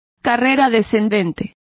Complete with pronunciation of the translation of downstroke.